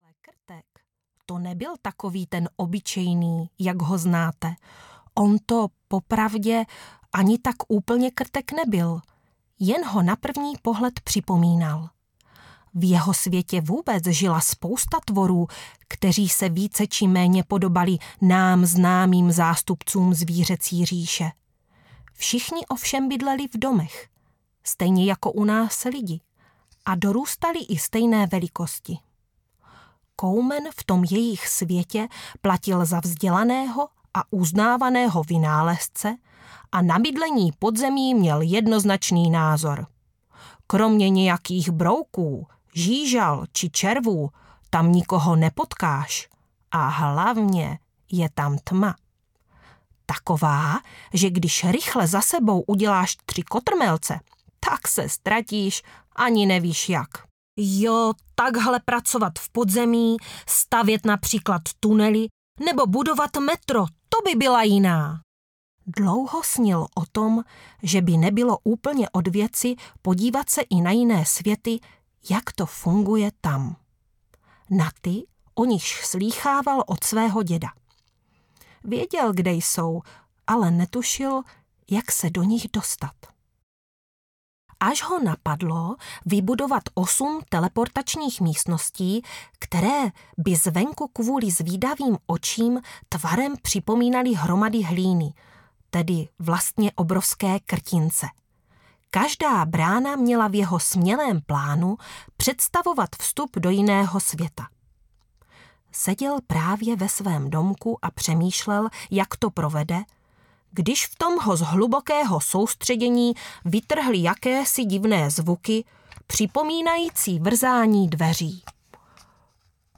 Meďan a Koumen na planetě pupkáčů audiokniha
Ukázka z knihy